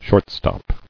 [short·stop]